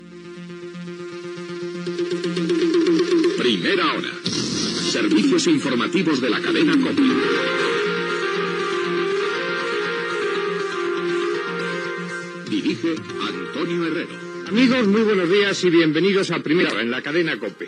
Careta i inici del programa
Informatiu